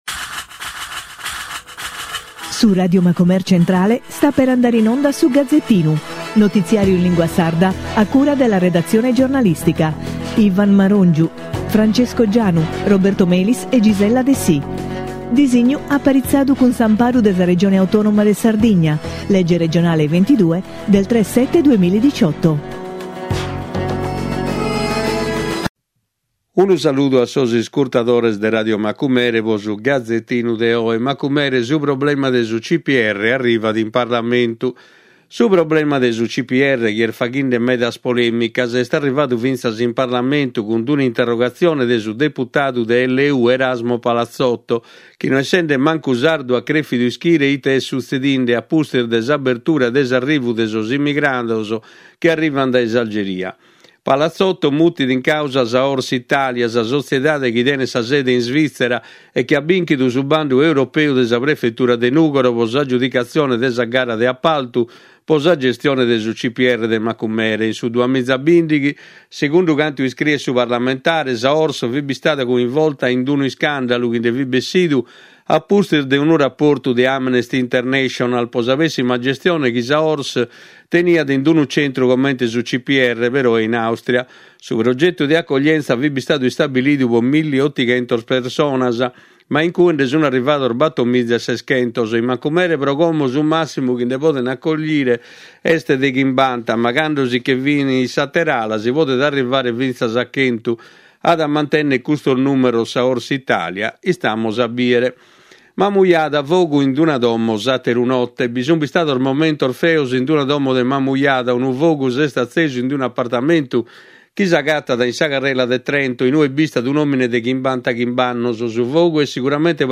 Notiziario in lingua sarda con la consulenza di esperti. Le principali notizie nazionali e nel dettaglio quelle regionali con particolare riferimento all’attività socio economica e culturale della nostra isola con un occhio particolare al mondo dei giovani.